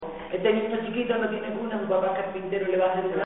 Materia / geográfico / evento: Canciones de cuna Icono con lupa
Secciones - Biblioteca de Voces - Cultura oral